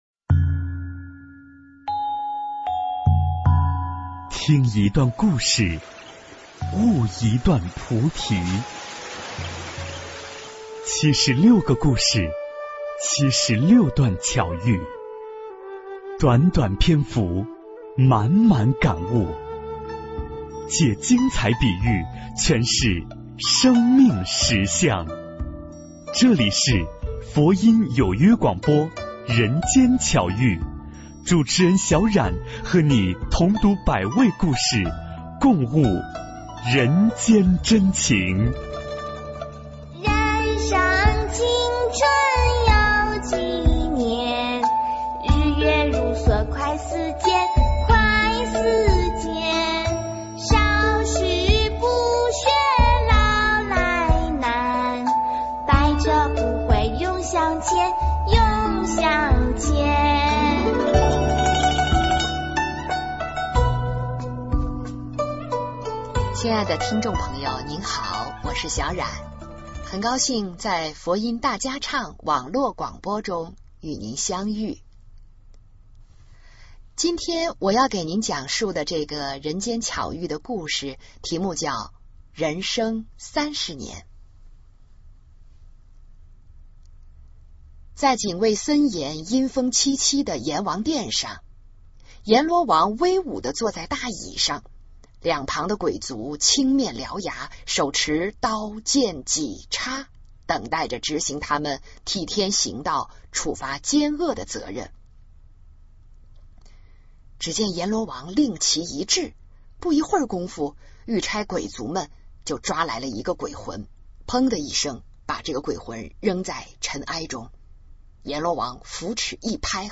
10人生三十年--诚敬仁和 真言 10人生三十年--诚敬仁和 点我： 标签: 佛音 真言 佛教音乐 返回列表 上一篇： 09夫妻争饼--诚敬仁和 下一篇： 22杀子成担--诚敬仁和 相关文章 地藏经05--梦参法师 地藏经05--梦参法师...